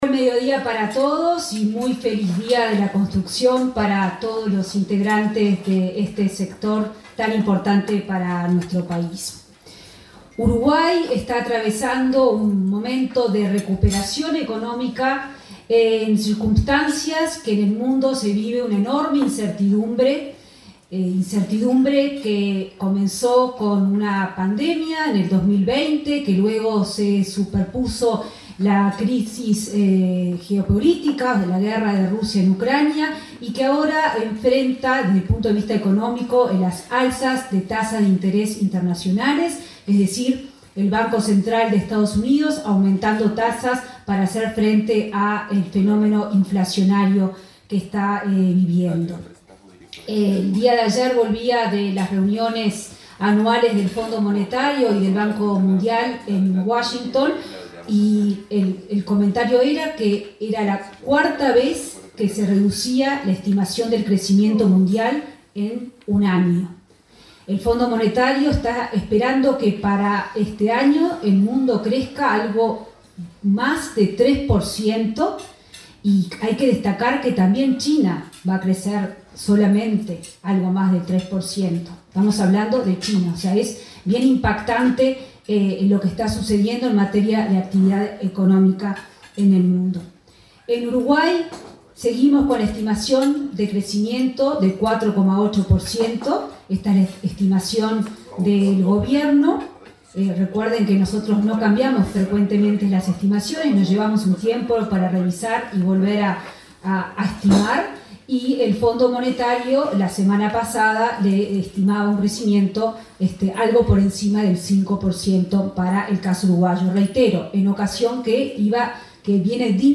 Palabras de la ministra de Economía, Azucena Arbeleche
La ministra de Economía, Azucena Arbeleche, participó este lunes 17 en el Club de Golf, en Montevideo, en un acto por el Día de la Construcción.